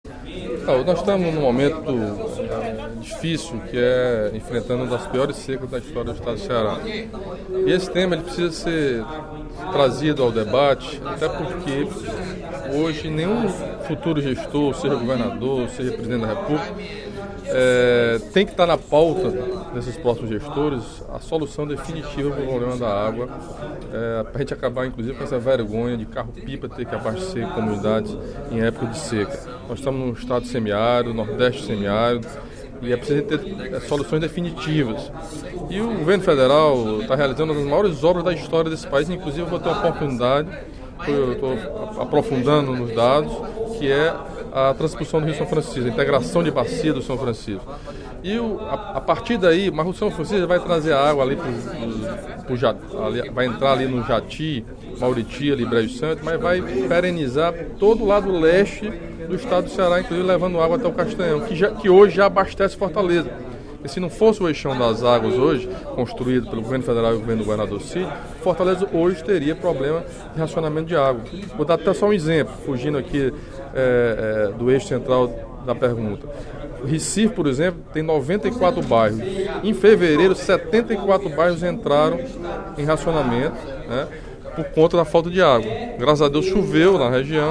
No primeiro expediente da sessão plenária desta quarta-feira (23/10), o deputado Camilo Santana (PT) ressaltou as ações do Governo do Estado, em parceria com o Governo Federal, para resolver de forma definitiva o problema de abastecimento d'água no Ceará.